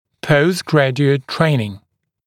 [ˌpəust’grædjuət ‘treɪnɪŋ] [-ʤu-][ˌпоуст’грэдйуэт ‘трэйнин] [-джу-]последипломное обучение